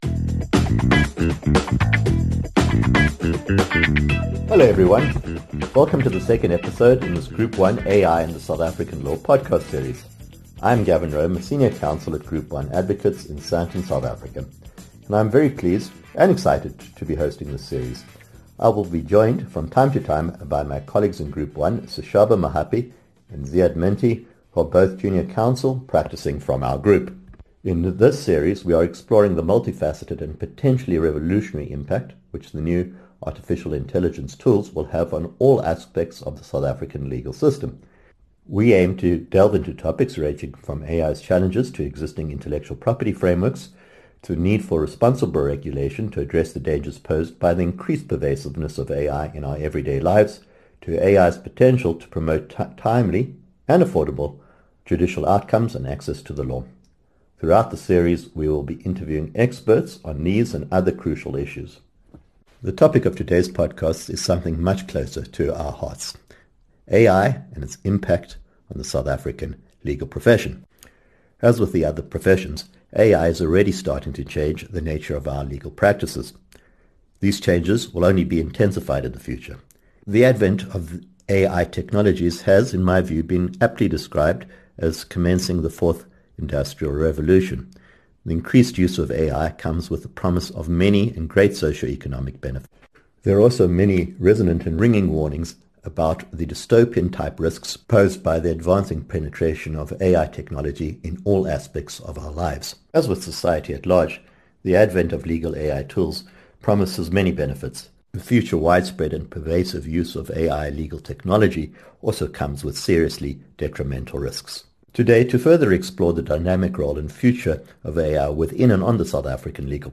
Episode overview:In this conversation